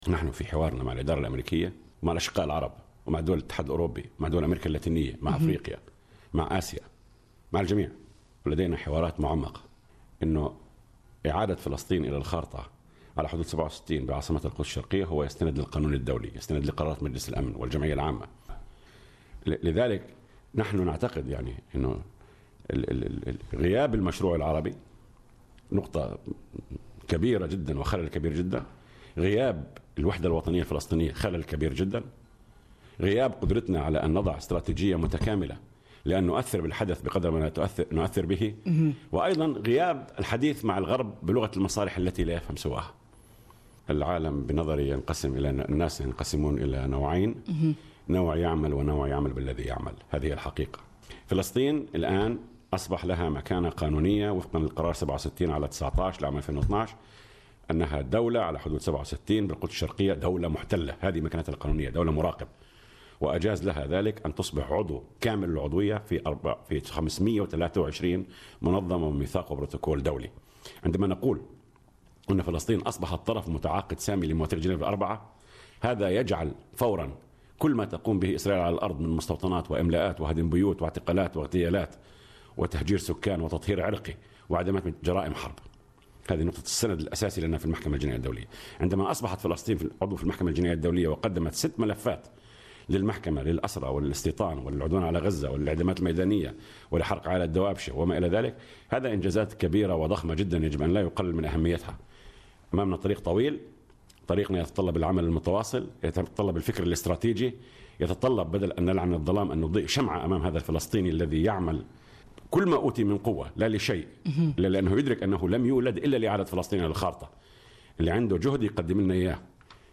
صائب عريقات للإذاعة: أتوقع أن تفتح المحكمة الجنائية الدولية تحقيقا قضائيا ضد قادة اسرائيل في 2016 | الإذاعة الجزائرية